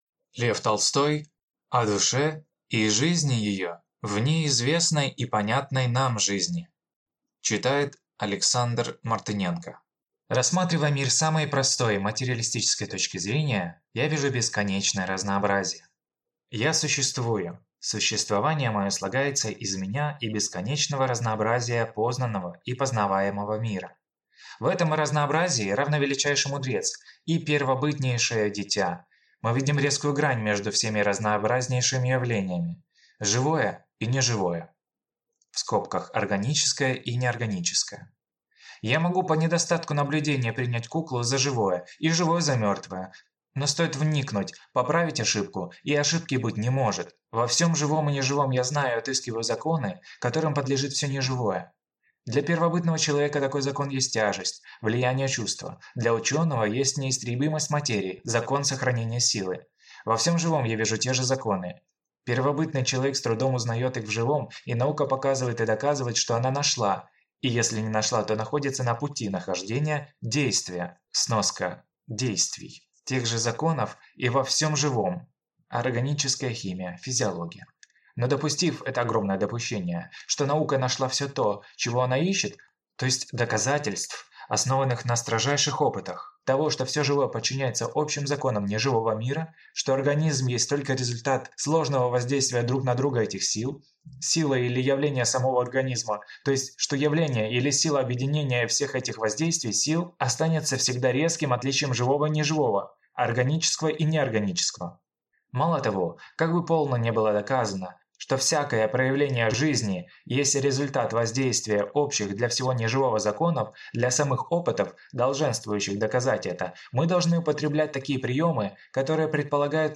Аудиокнига О душе и жизни ее вне известной и понятной нам жизни | Библиотека аудиокниг